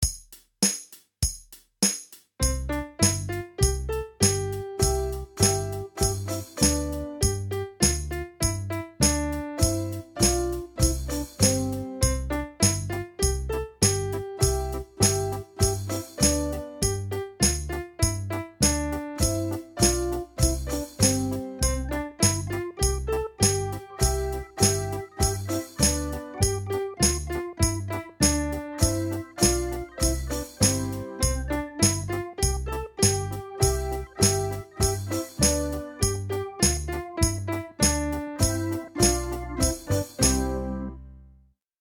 vánoční koledu